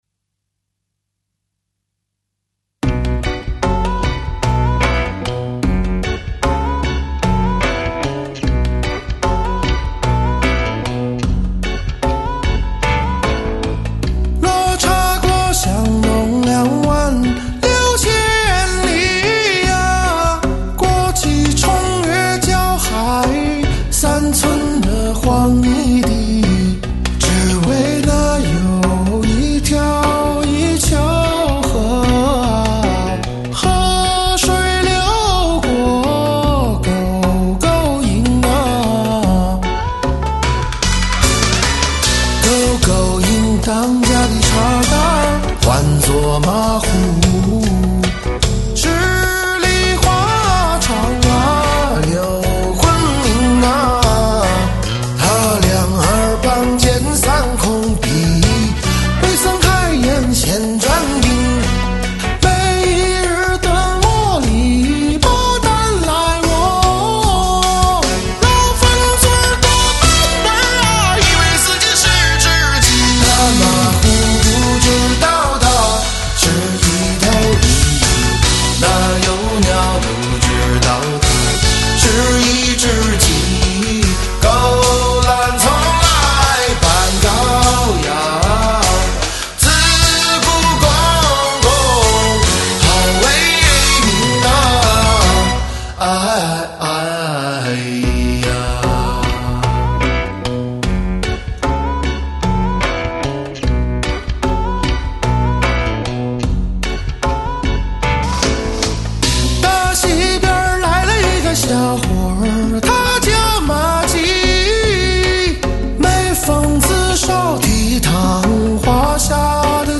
音乐风格：WORLD MUSIC